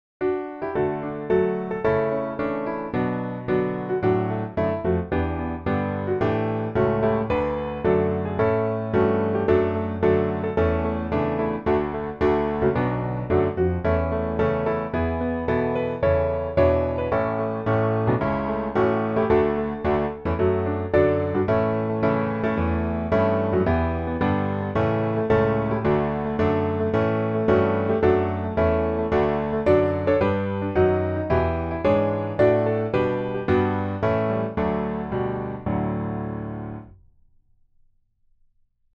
D大調